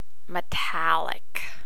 Listen to me pronouncing the terms one can use to describe the luster of a mineral.